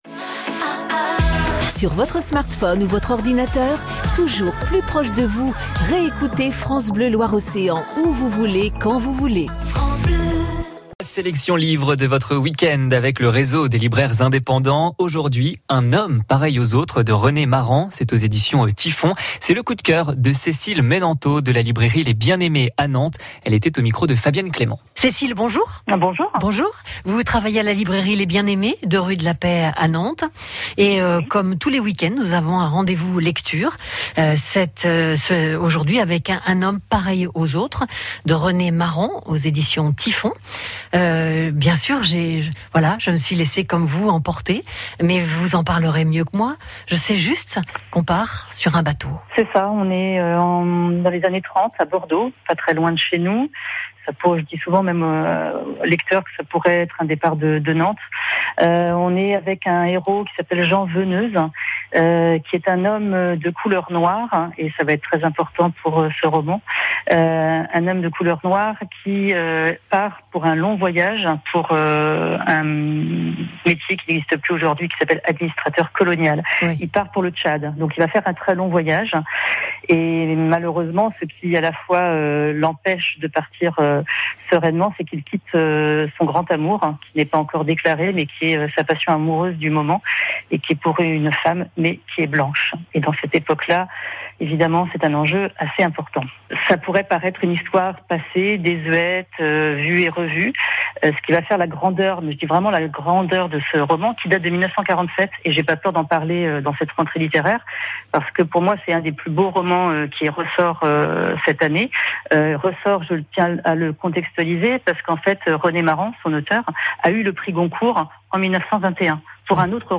En partenariat avec ICI Loire Océan, écoutez les chroniques de vos libraires, les samedis et dimanches à 8h20